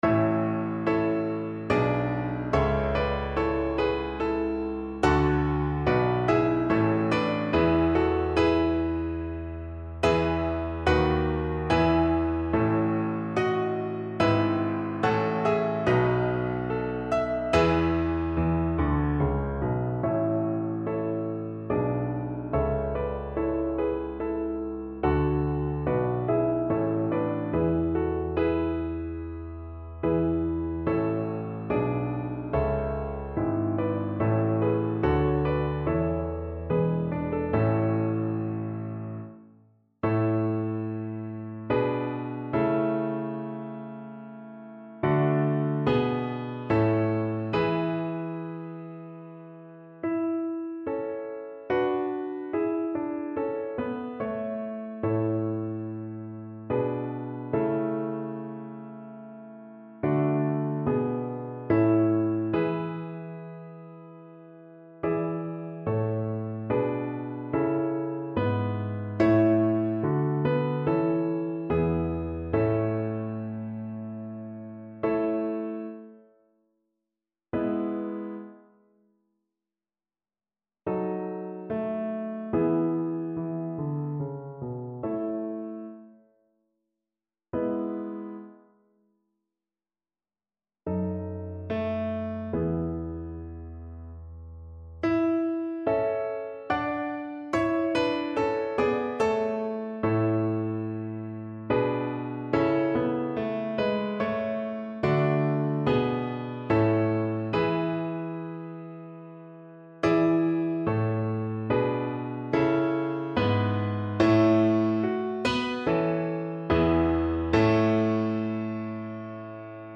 Italian Baroque composer.
Tempo di Minuetto
Italian Baroque Songs for Soprano